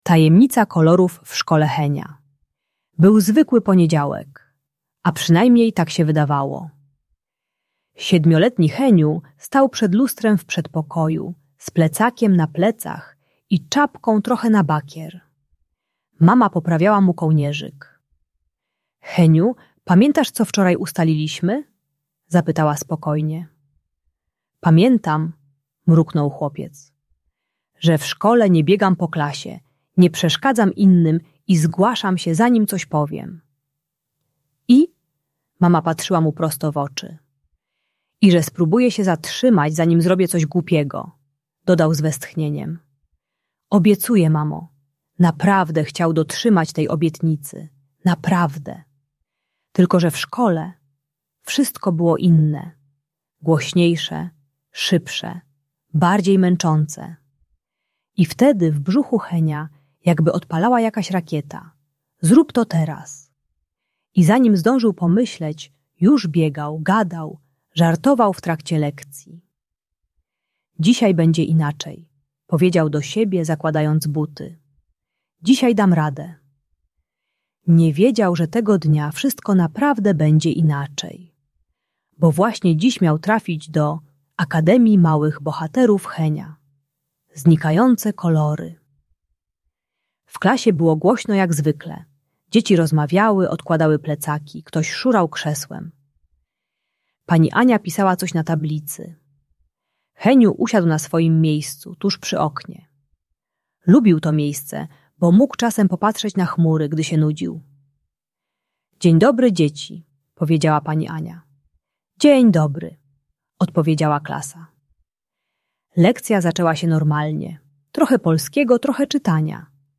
Ta audiobajka o impulsywności i ADHD pomaga dziecku zrozumieć, dlaczego trudno mu się zatrzymać zanim coś zrobi. Uczy techniki STOP - zatrzymaj się, pomyśl, oceń, postąp mądrze.